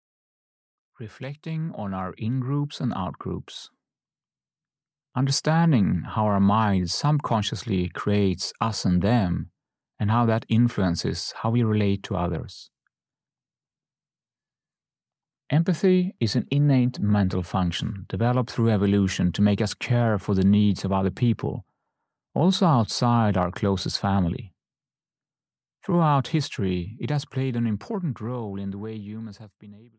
Audio knihaReflection on our In-Groups and Out-Groups (EN)
Ukázka z knihy